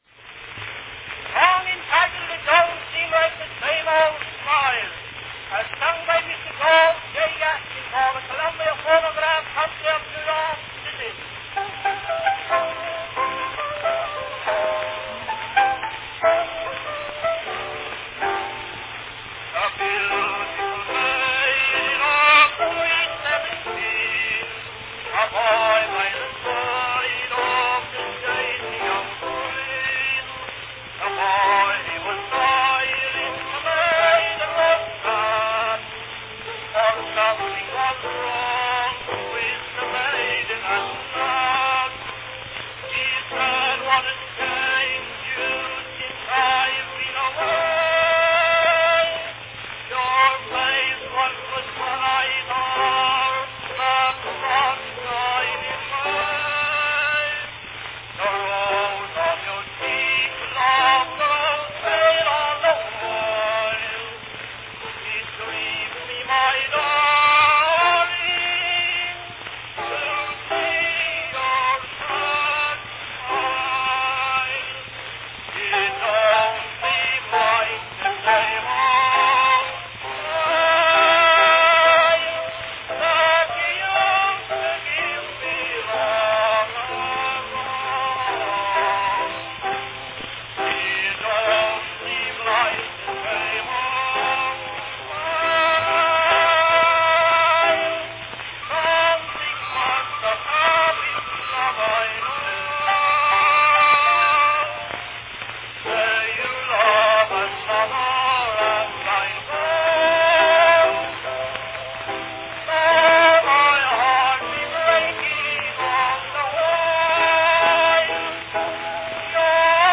Cylinder of the Month
Category Song
A beautiful sentimental song sung by one of the top tenors of popular songs during the 1890's.